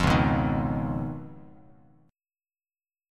Db9 Chord
Listen to Db9 strummed